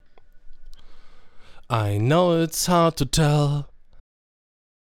Was ich jetzt höre: das Weiß löst besser auf.
Bei Rode ist alles gleich, wie ein Multibandlimiter in der Billigklasse, macht alles flach.